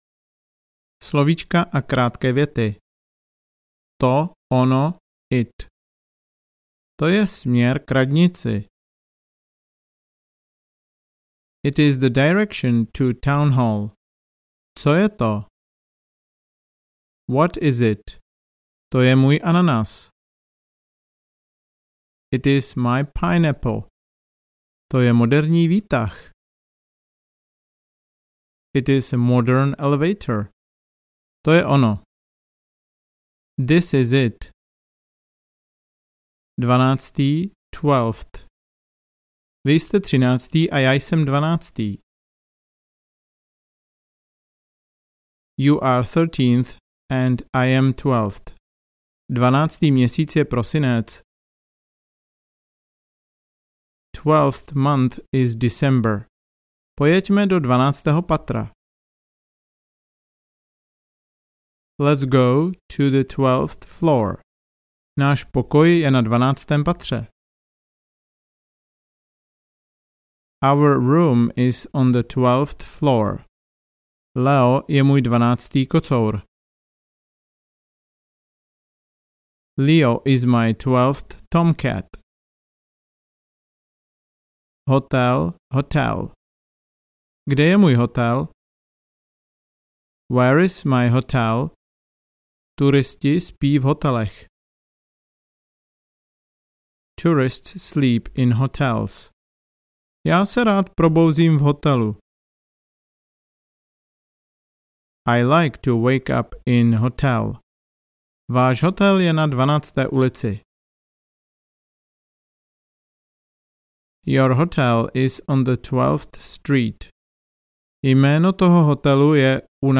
Konverzace pro věčné začátečníky a mírně pokročilé v 50 rozhovorech.
Ukázka z knihy